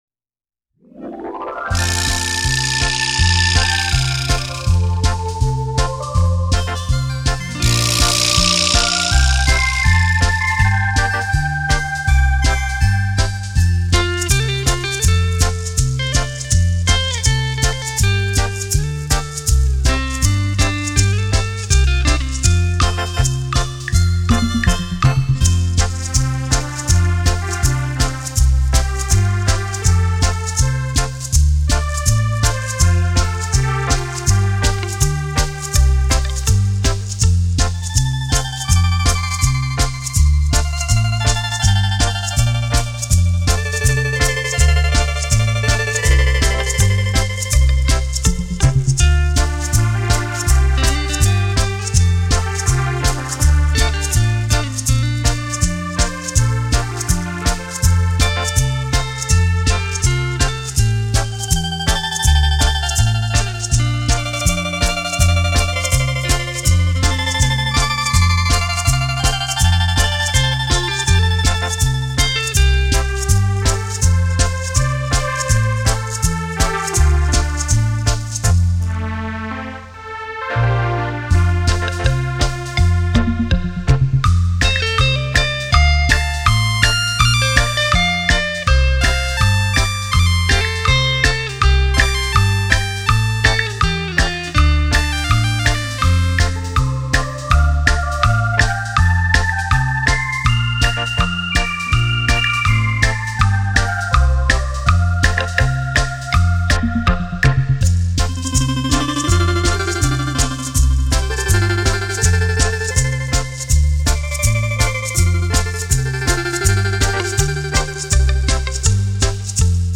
专辑类型：电子琴音乐